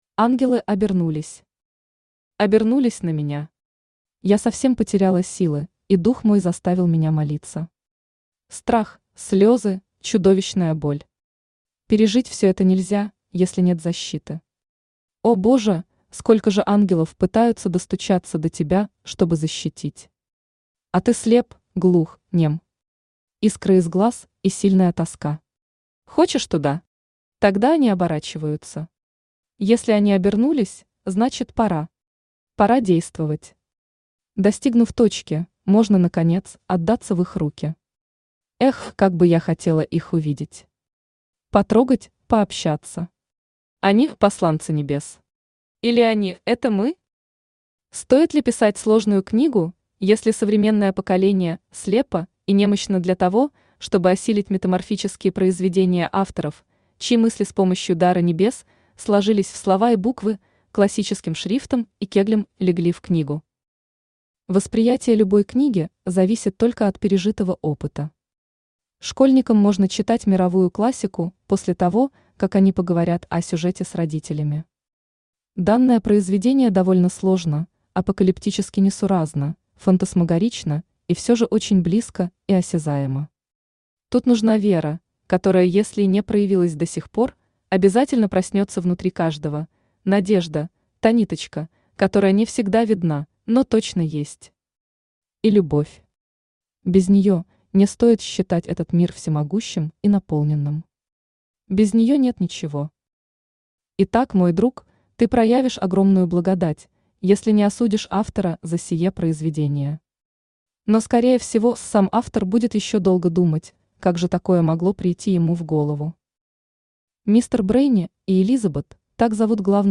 Аудиокнига Ангелы обернулись | Библиотека аудиокниг
Aудиокнига Ангелы обернулись Автор Елена Владимировна Есаулова Читает аудиокнигу Авточтец ЛитРес.